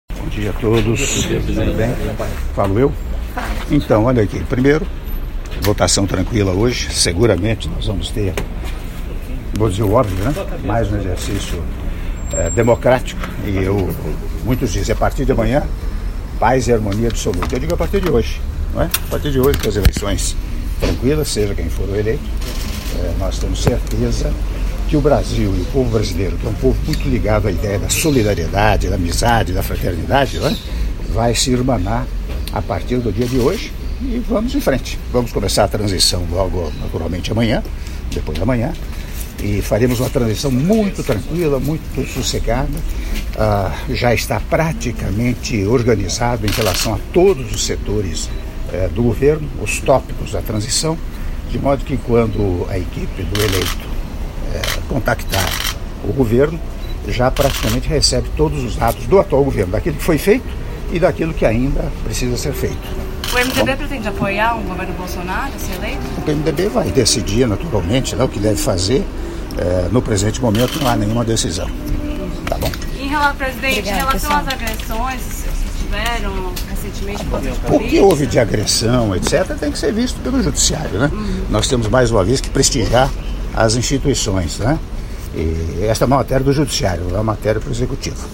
Áudio da entrevista coletiva do senhor Presidente da República, Michel Temer, após votação do segundo turno -São Paulo/SP- (1min28s)